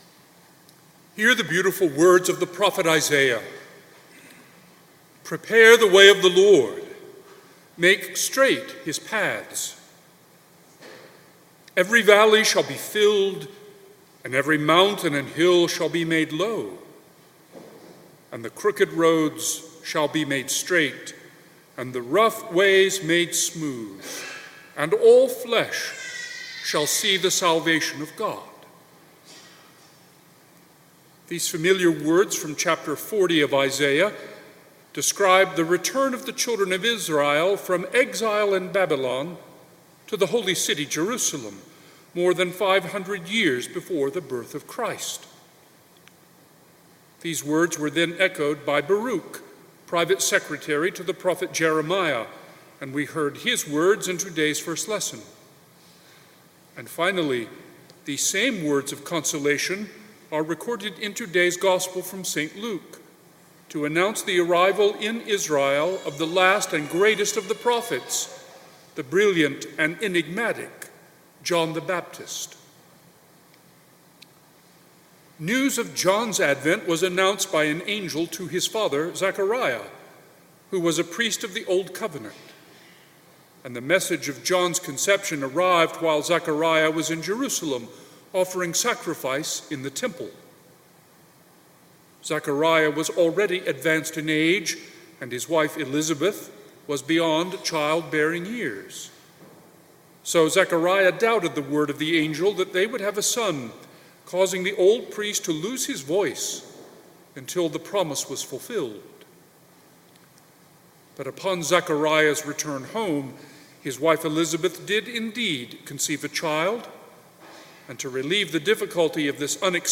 Homily Audio Archives - St. Mary's Catholic Church